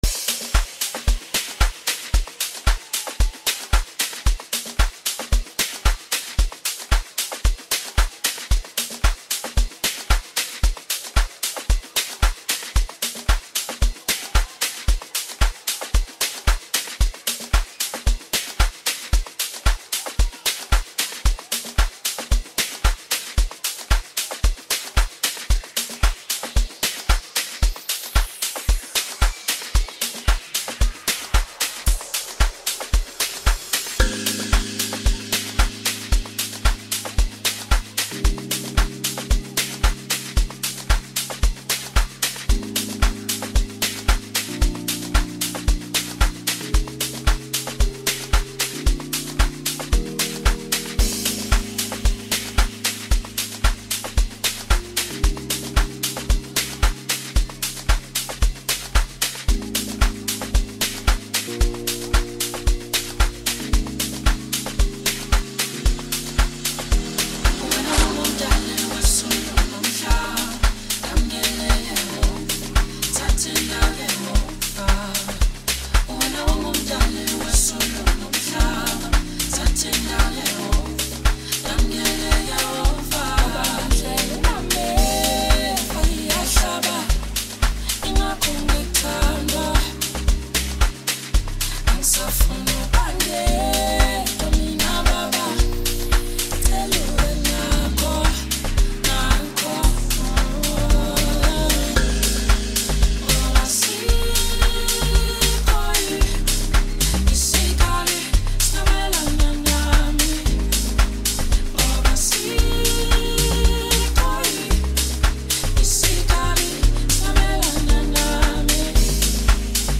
smooth sound, balanced mood